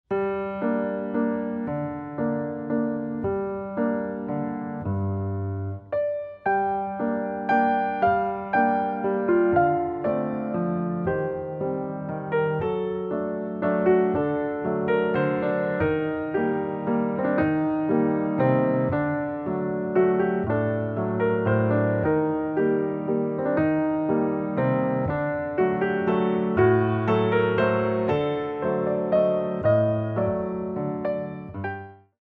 Ballet Class Music
29 Original Piano Pieces for Ballet Class
Warm-Up
mod. 3/4 - 1:50